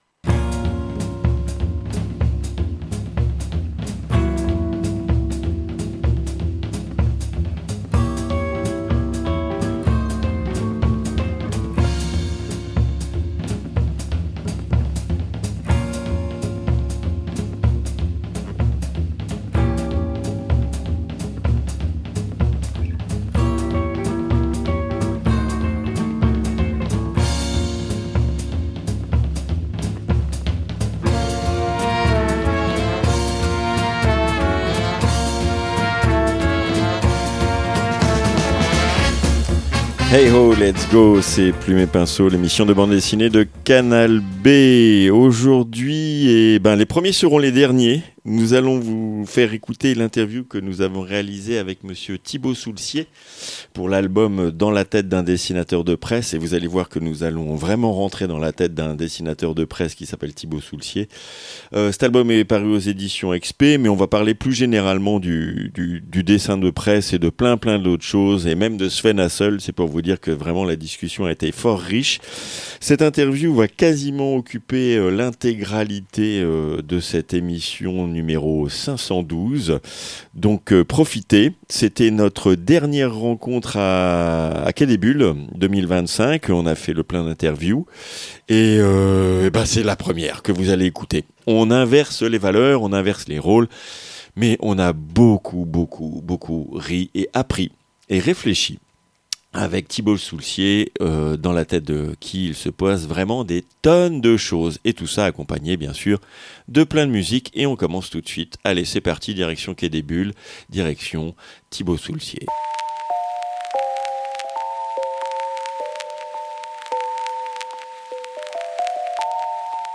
II - INTERVIEW